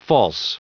Prononciation du mot false en anglais (fichier audio)
Prononciation du mot : false